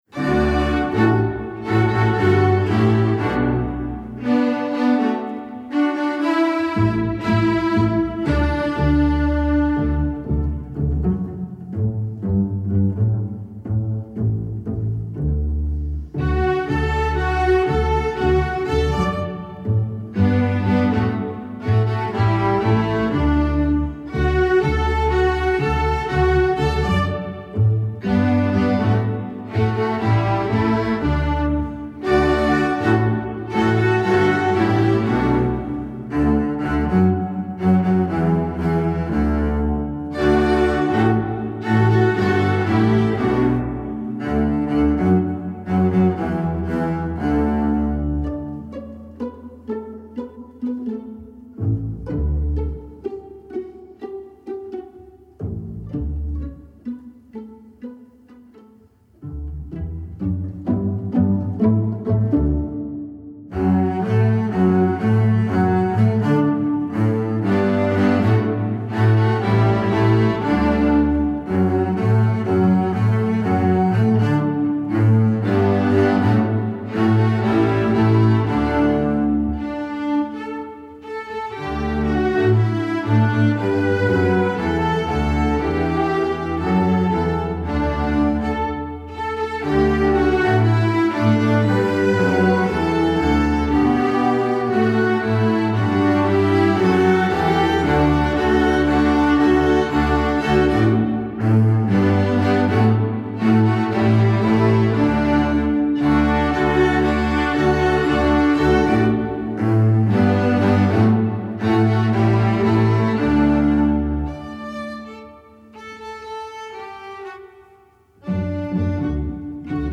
Composer: Jamaican Folk Song
Voicing: String Orchestra